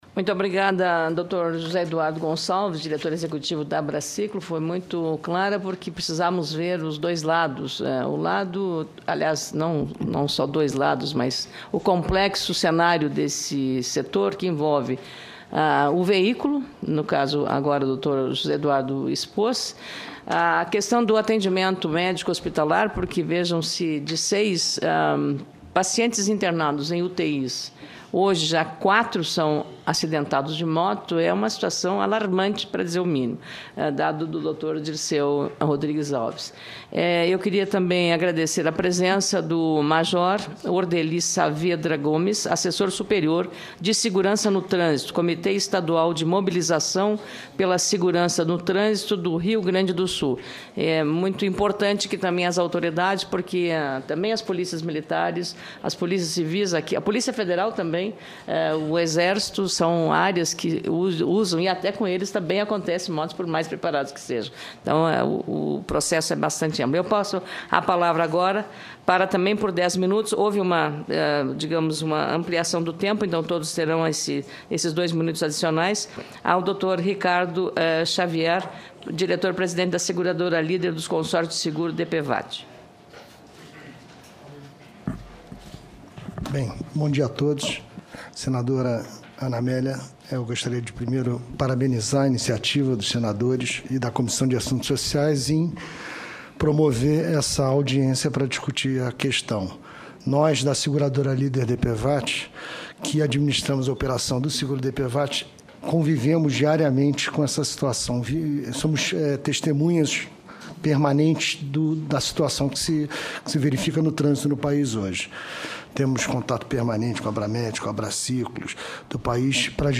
Confira a íntegra dos principais debates da Comissão de Assuntos Sociais do Senado